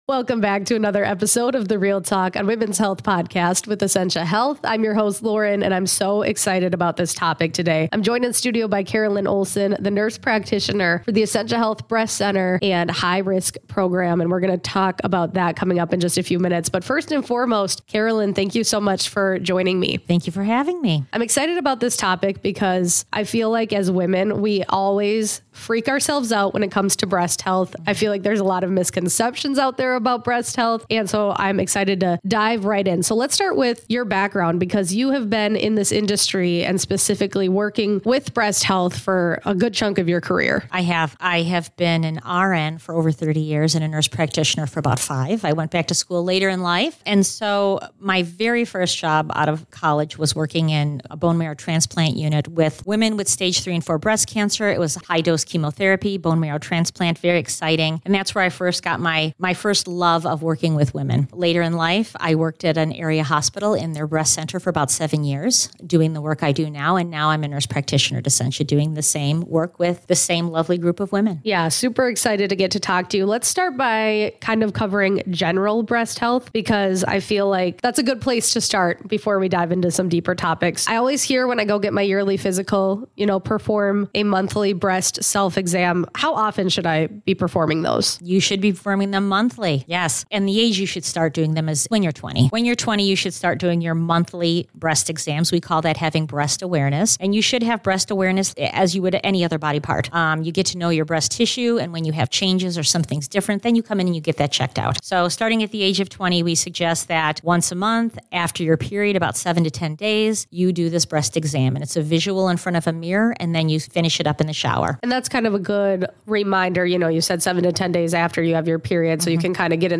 We talk abut what it means to have breast awareness, how it could save your life, and more, including: - how often women should perform self breast exams - when women should start getting mammograms - what it means to have dense breasts - and more. Broadcast on: 10 Oct 2024